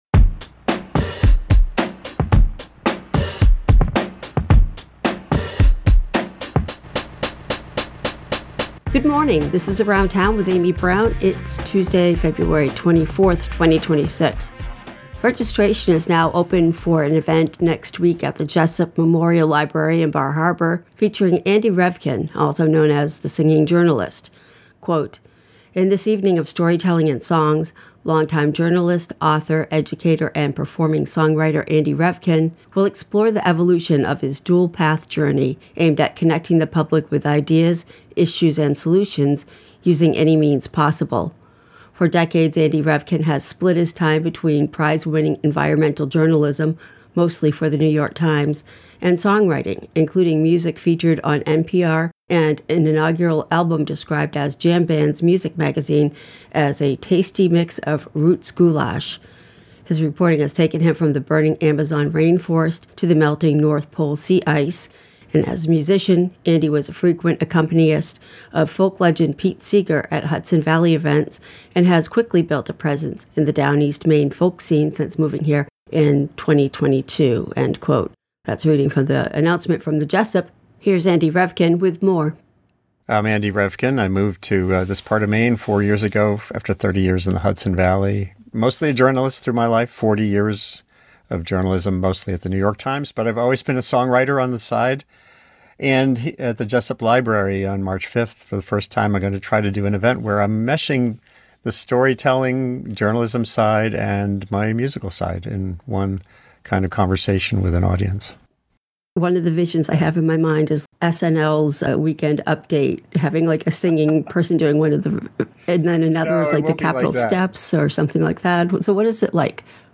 The Singing Journalist, Andy Revkin, joins us today with an invitation to his upcoming talk at the Jesup Memorial Library, Thursday 3/5 at 6:30pm